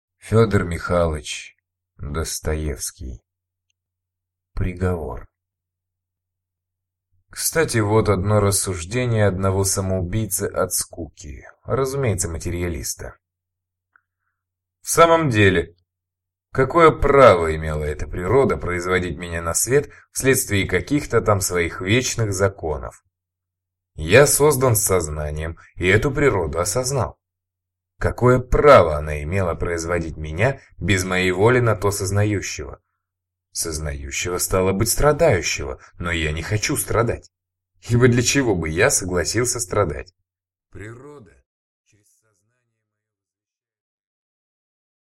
Aудиокнига Приговор